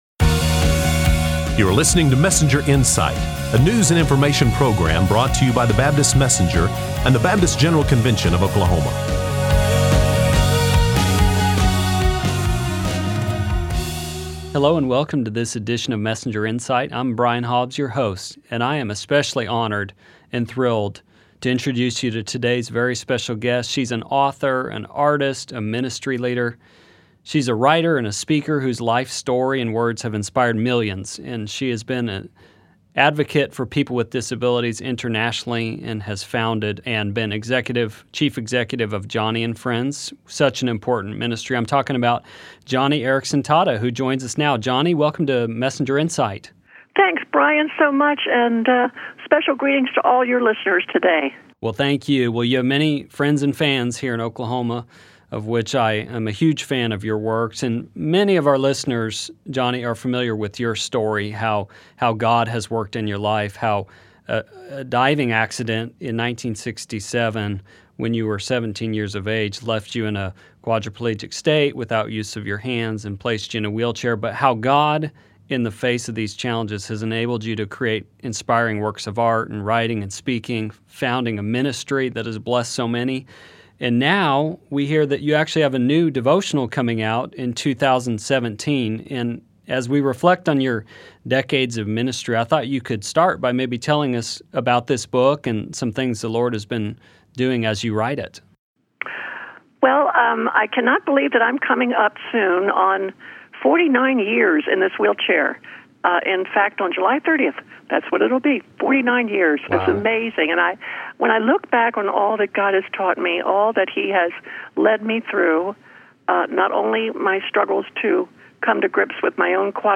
An Interview with Joni Eareckson Tada Admired author, artist and Christian leader Joni Eareckson Tada speaks about faith, the value of life and about a forthcoming devotional book.